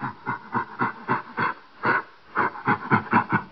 sounds_gorilla_grunt.ogg